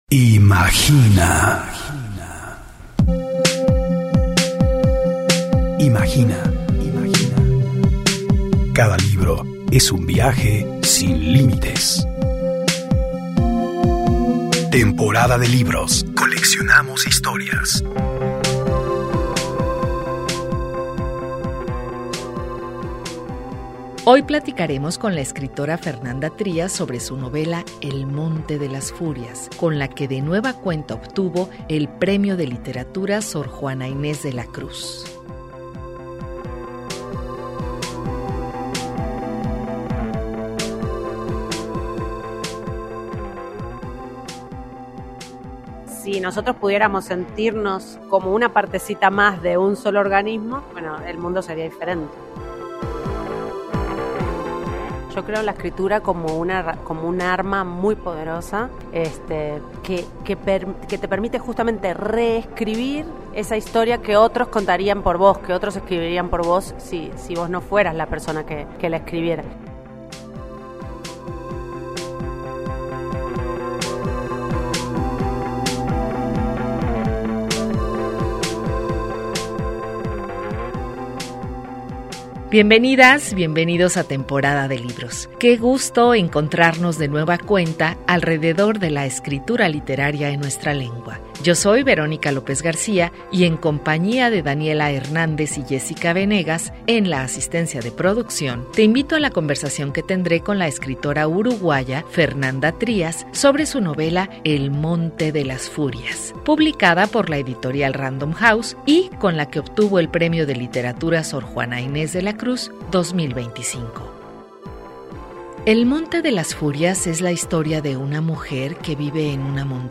conversa con Fernanda Trías, autora del libro "el monte de furias" una novela que narra la historia de una mujer aislada que cuida los linderos de una montaña agreste, lidiando la soledad y una tensa relación con el entorno.